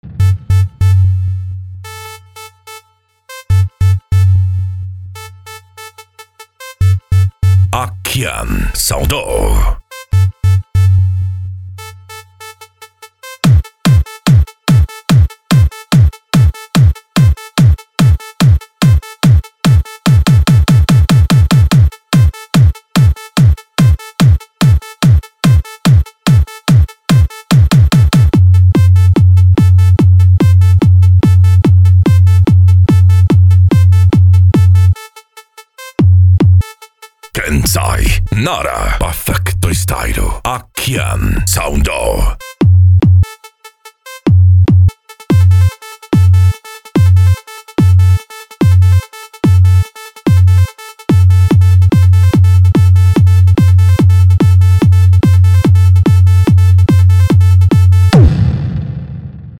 Deep House
Eletronica
Hard Style
PANCADÃO